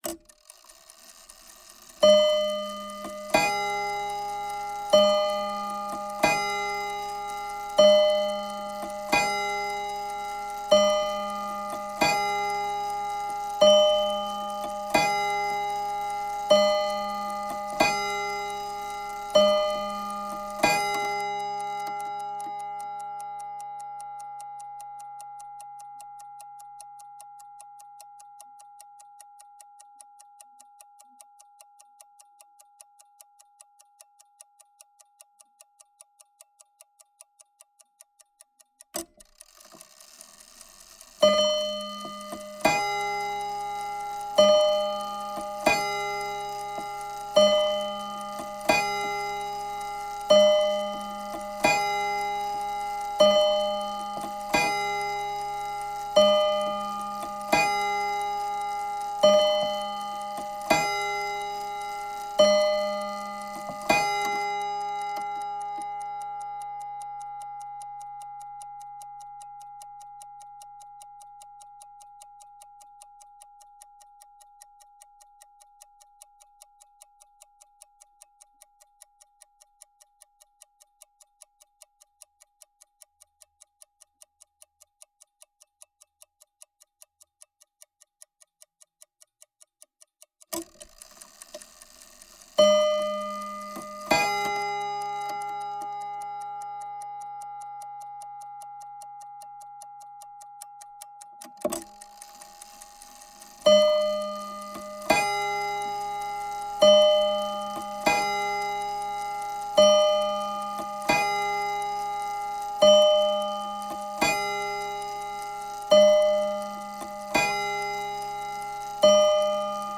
Old Desk Clock Chimes Ring….mp3 📥 (5.61 MB)